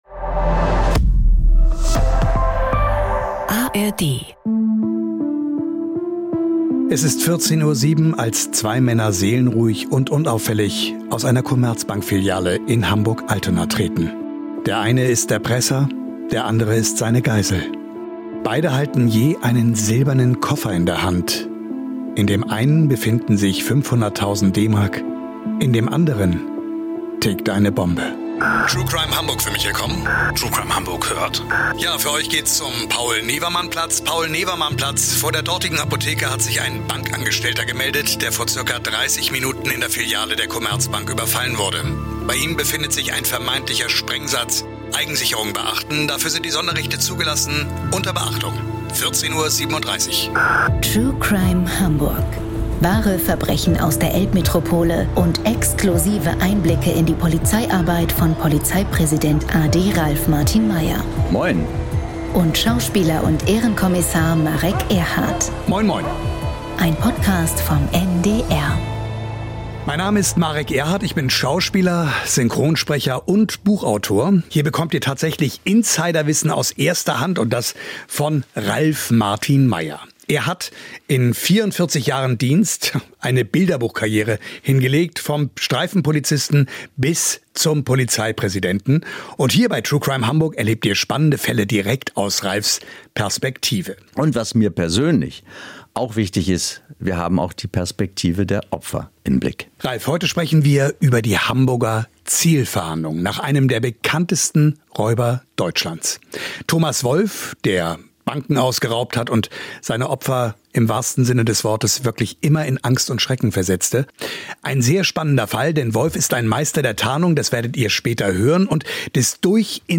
Über Jahre lebt er im Untergrund, bis seine Flucht am 28. Mai 2009 auf der Reeperbahn ein abruptes Ende findet. Im Gespräch mit Schauspieler und Ehrenkommissar Marek Erhardt erzählt Hamburgs Polizeipräsident a.D. Ralf Martin Meyer vom Katz- und Mausspiel mit dem Ganoven und beschreibt, wie Zielfahnder dem gewieften Erpresser letztendlich doch auf die Schliche kamen.